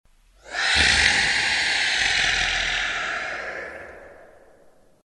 MosterWisp.wav